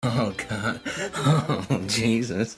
Oh jesus Sound Effect